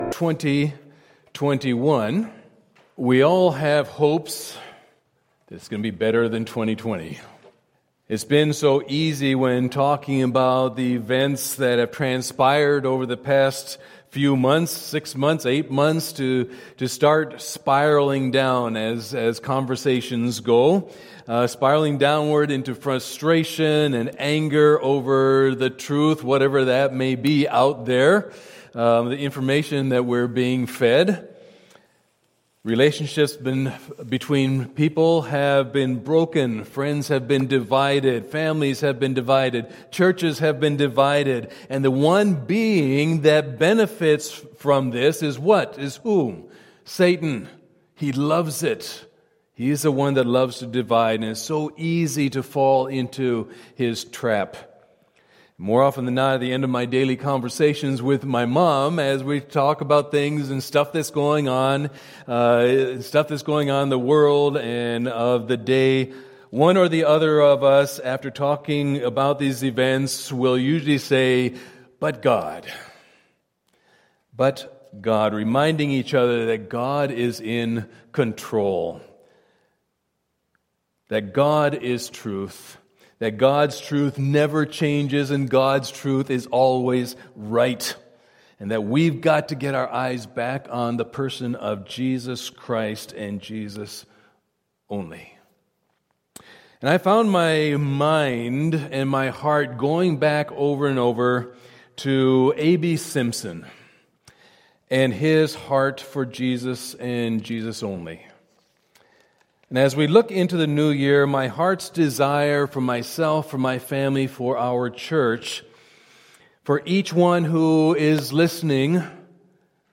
2020 Himself Preacher